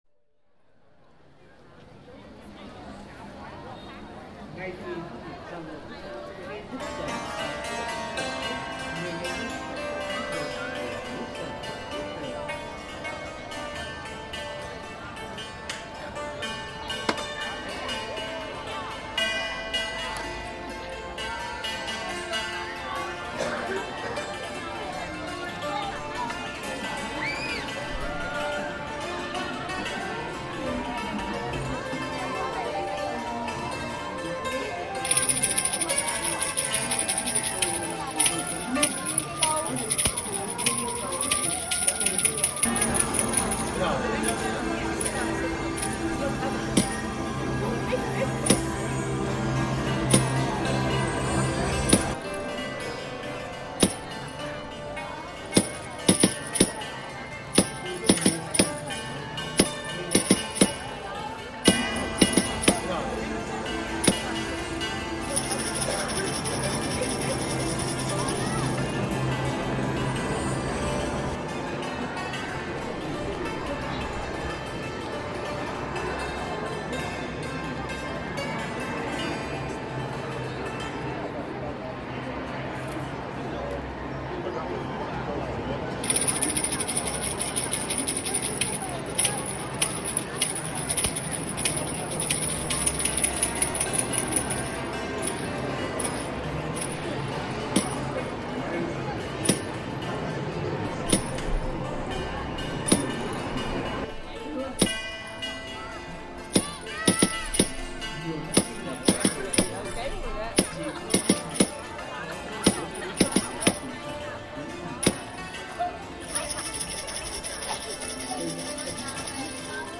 Festival sounds in Hanoi, Vietnam